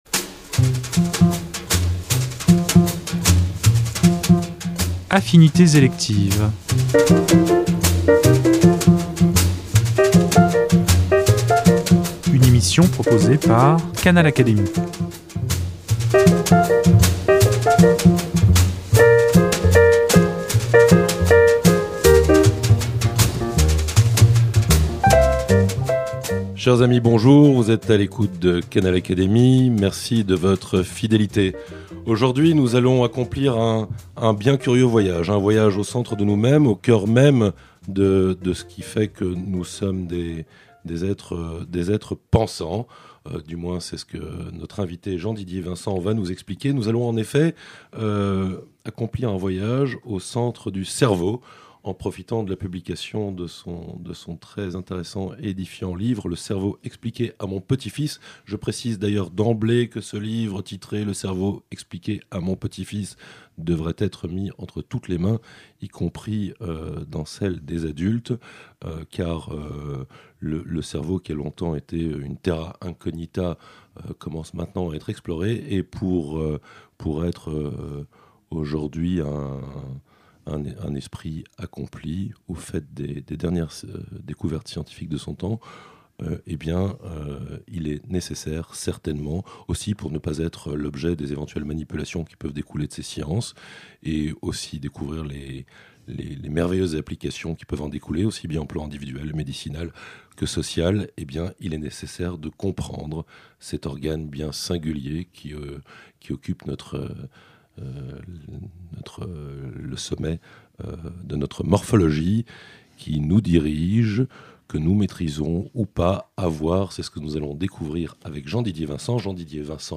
Dans Le cerveau expliqué à mon petit-fils (Editions du Seuil, 2016), comme dans l’entretien qu’il nous a accordé, ce grand scientifique a relevé le défi de nous présenter cet organe singulier avec des mots simples et des exemples concrets. Un pari réussi qui nous mène toutefois aux frontières de la philosophie et même de la métaphysique car, loin d’être seulement un super logiciel, le cerveau humain est aussi le lieu où s’élaborent le désir et le plaisir, la souffrance et la beauté…